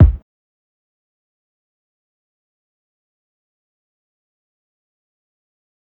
Kick (Random).wav